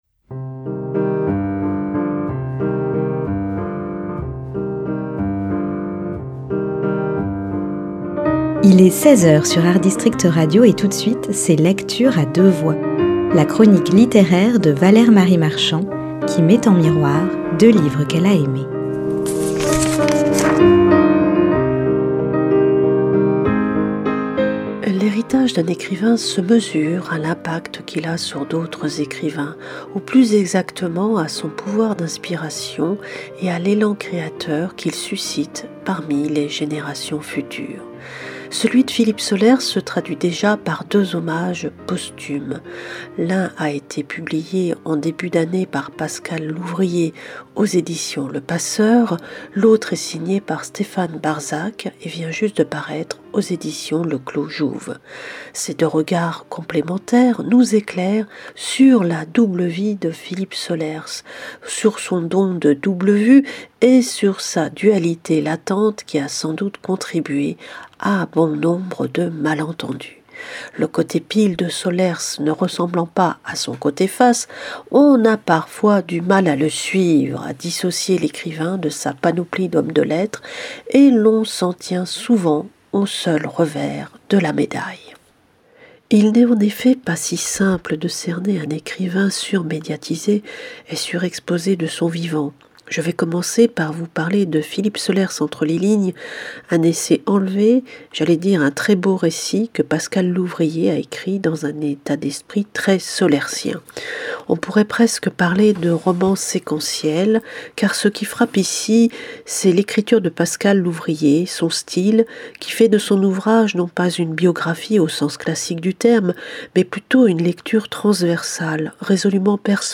Chronique littéraire